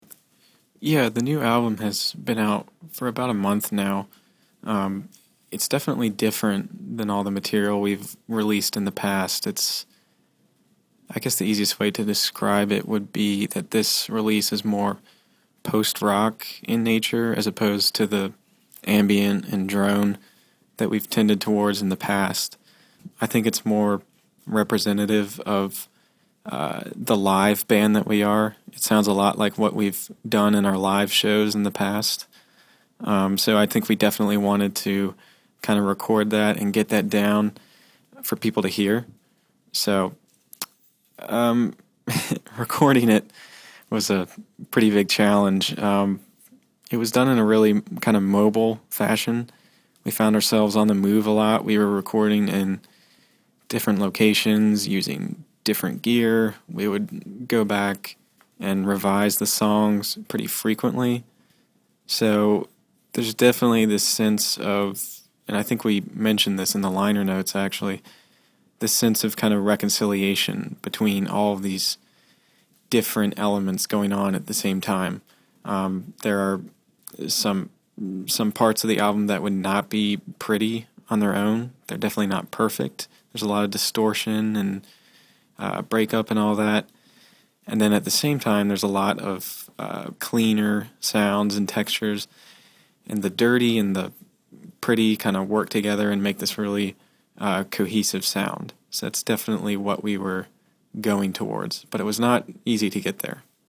THE SOUND OF RESCUE INTERVIEW – August 2012